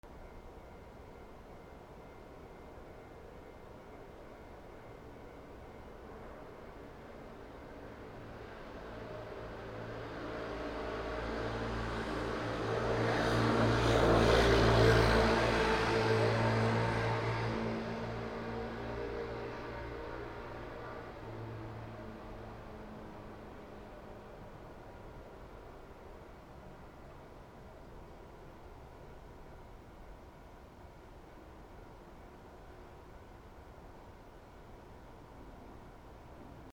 車通過
/ C｜環境音(人工) / C-57 ｜再構成用_車通過